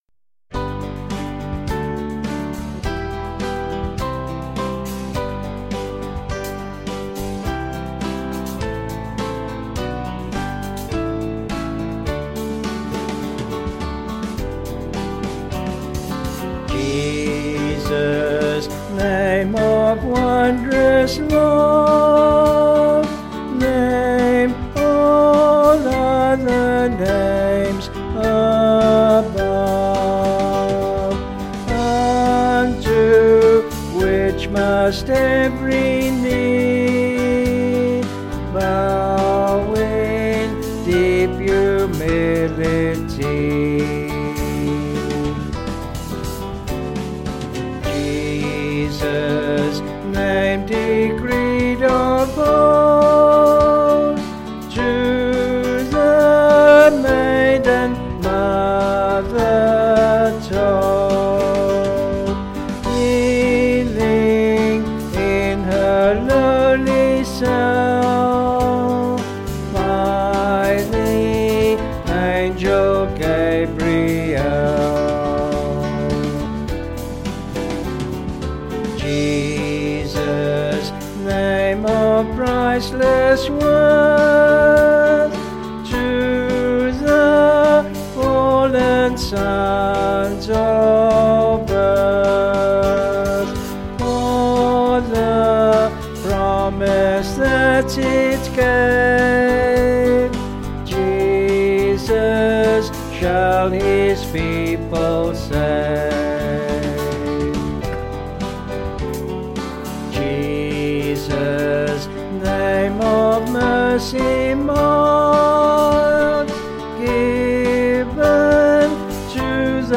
Vocals and Band   266.1kb Sung Lyrics